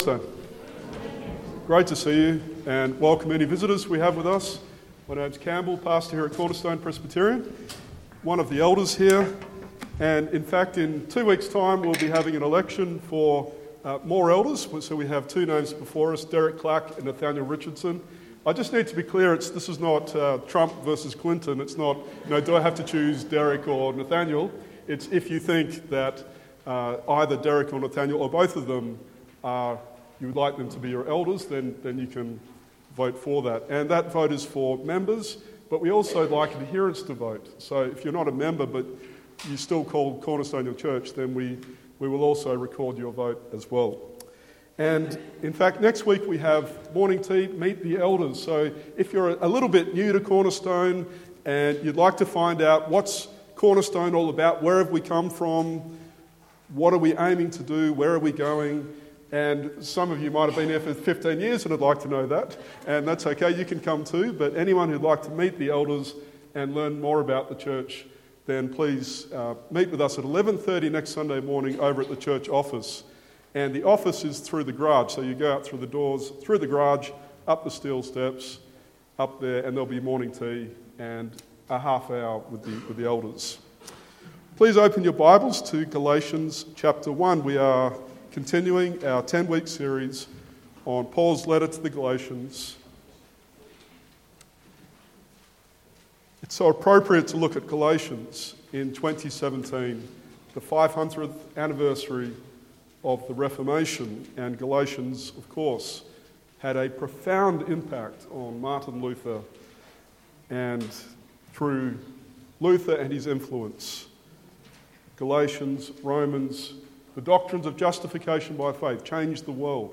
Galatians 1:11-2:10 Sermon